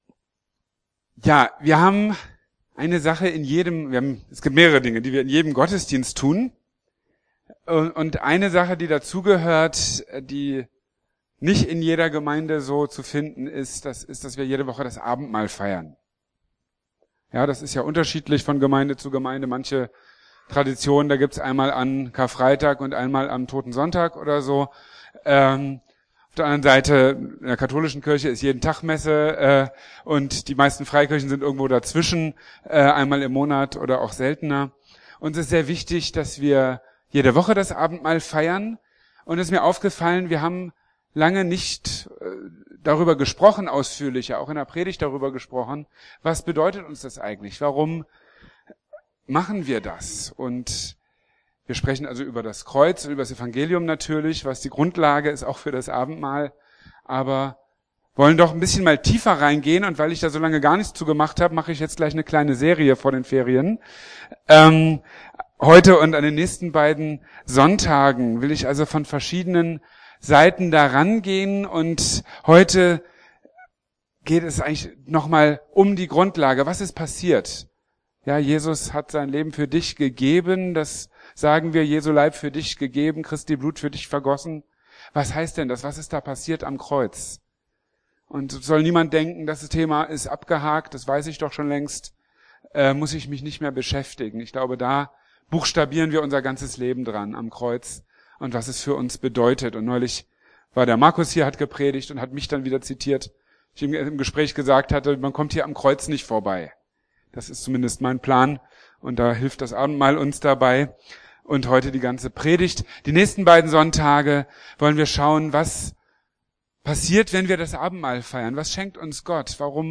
Lass dich versöhnen mit Gott! (Predigtreihe Abendmahl, Teil 1) | Marburger Predigten